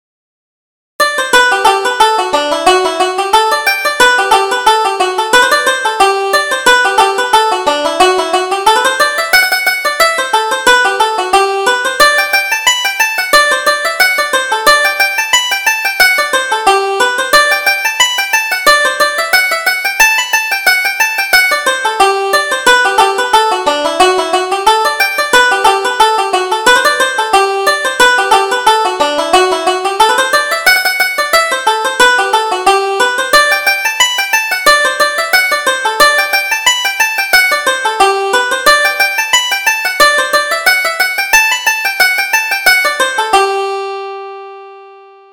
Reel: The Lightning Flash